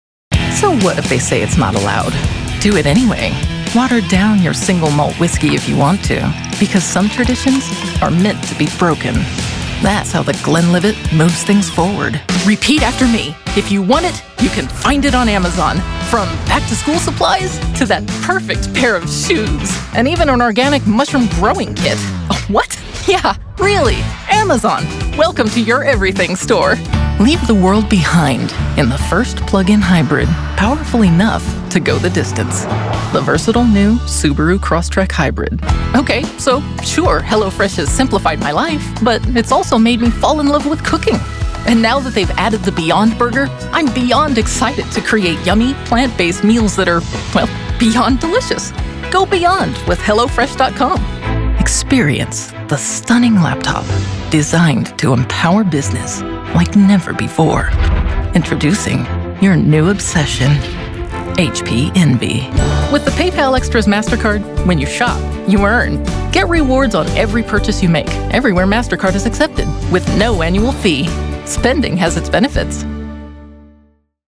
Confident, approachable, sincerity that builds connection.
Commercials, attitude, relatable, humor, serious, flirty
General American, American West Coast
Middle Aged